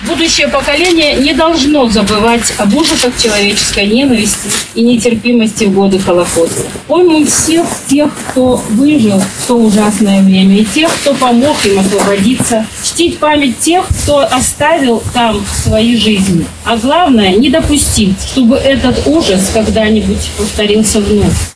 В мемориальном комплексе на могиле жертв фашистского террора — узникам Барановичского гетто на улице Чернышевского состоялся митинг — реквием, посвящённый Международному дню памяти жертв Холокоста.